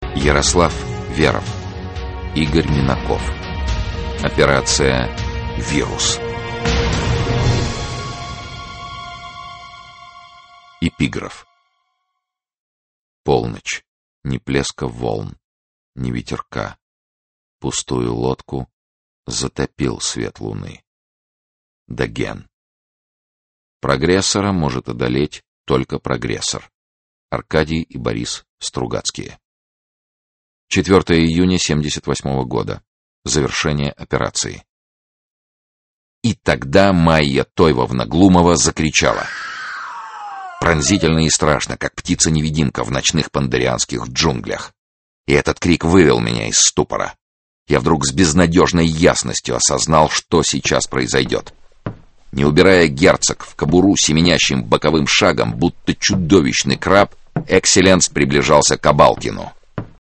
Aудиокнига Операция «Вирус» Автор Игорь Минаков Читает аудиокнигу Сергей Чонишвили.